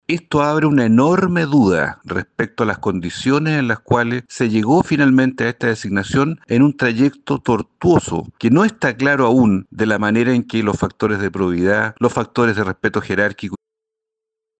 En ese sentido, el senador Juan Luis Castro (PS) mencionó que faltó transparencia en torno a las conversaciones que concluyeron en la designación de la exfiscal.